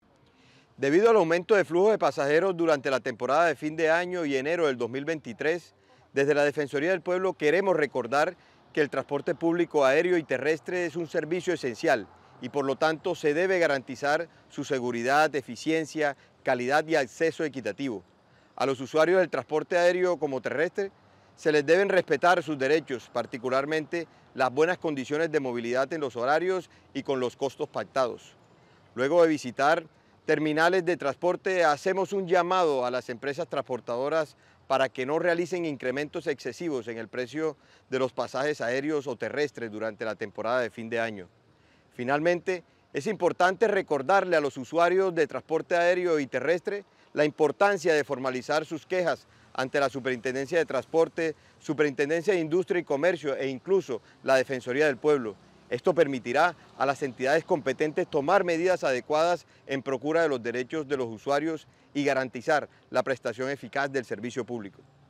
Defensor del Pueblo, Carlos Camargo